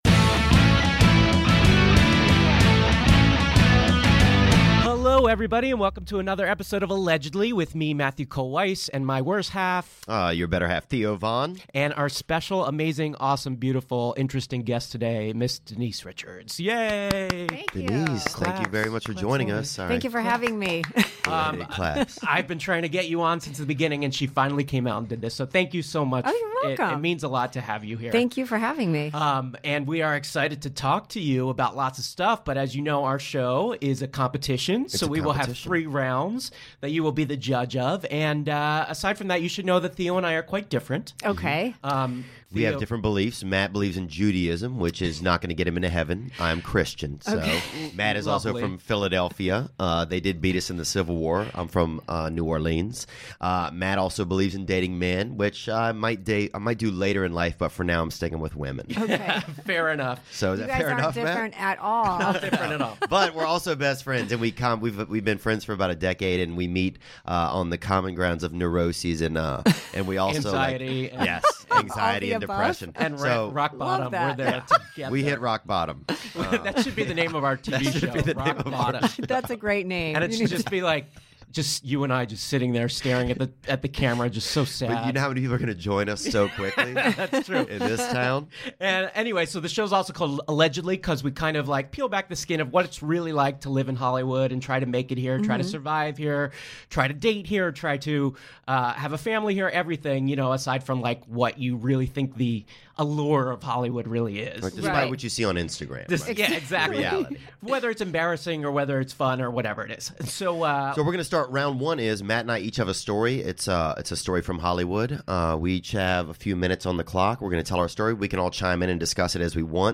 Though she doesn't talk about the HIV issue (this interview was recorded BEFORE the news broke), Richards has some interesting stuff to say about Charlie, their kids, and the wrong choices she's made in her life. On a happier side, she does have a friend with benefits and is hoping to find love again, but not before picking a podcast winner that is!